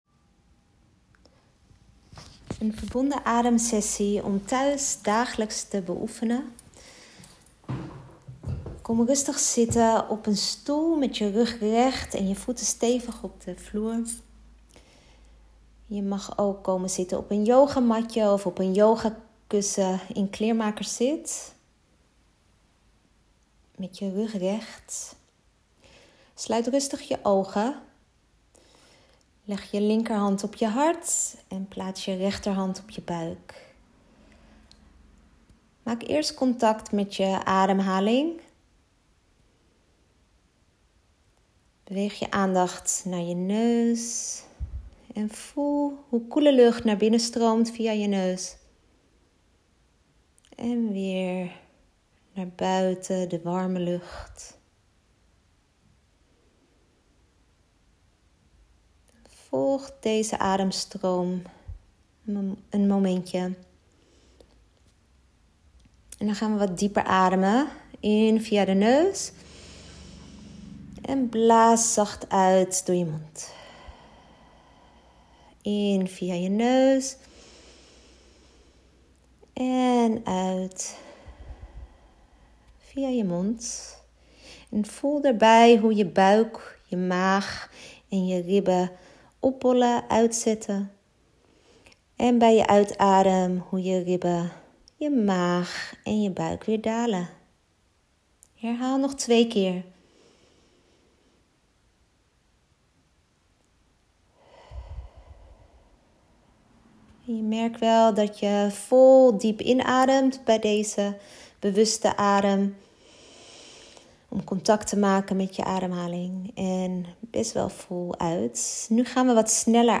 Verbonden Ademsessie At Home M 4 A